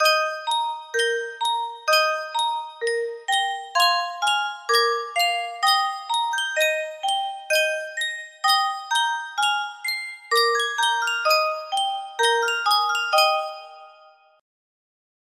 Sankyo Music Box - Mozart Eine Kleine Nachtmusik Romance KWA music box melody
Full range 60